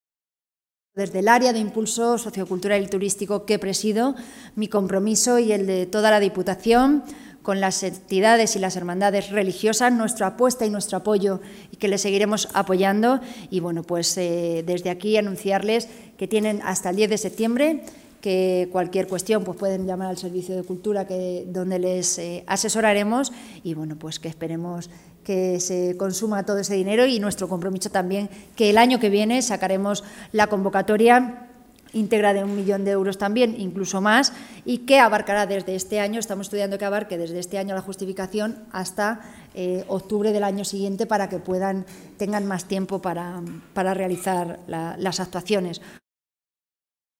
La vicepresidenta quinta de la Diputación, delegada del Área de Impulso Sociocultural y turístico, María Jesús Pelayo, ha ofrecido esta mañana una rueda de prensa para informar sobre la nueva convocatoria de la subvención, en régimen de concurrencia competitiva, para Cofradías, Hermandades y Entidades Religiosas de la provincia de Ciudad Real para la rehabilitación, conservación y adquisición de patrimonio cultural no protegido, dotada con 550.000 euros.